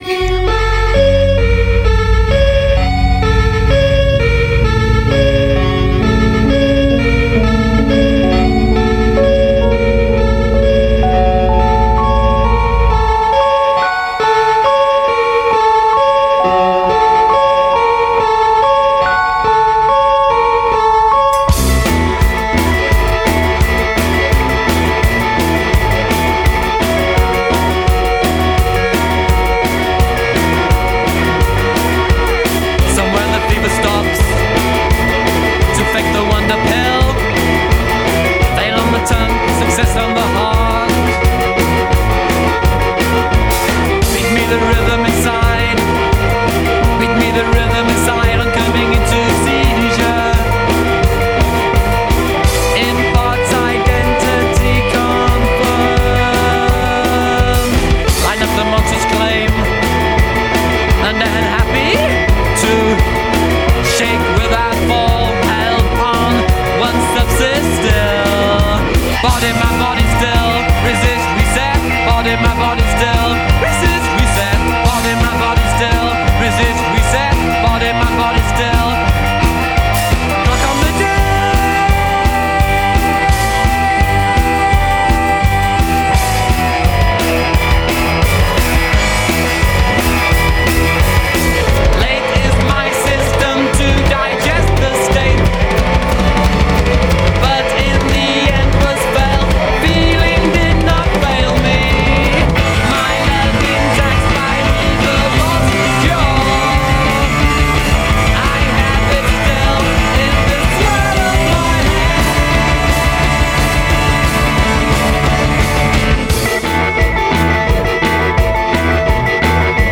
bass guitar
With a distinctively new wave sound